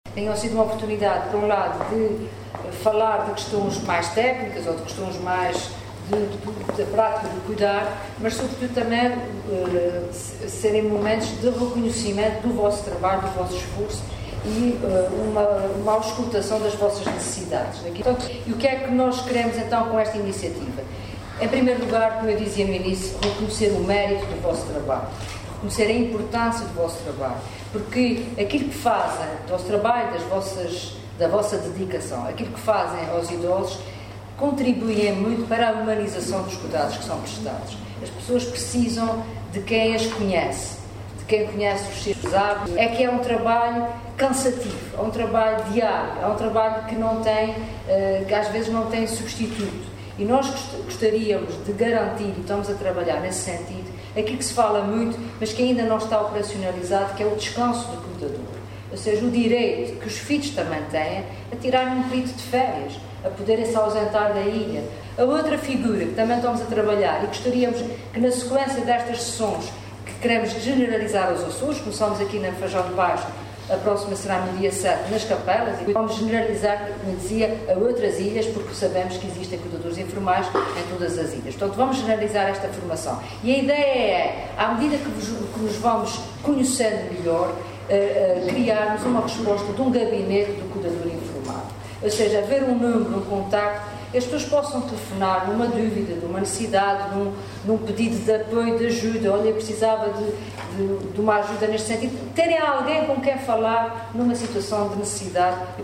Piedade Lalanda falava no encerramento do primeiro encontro do ciclo Conversas sobre o Cuidar”, que decorreu hoje no Centro Social e Cultural da Casa do Povo da Fajã de Baixo, em Ponta Delgada.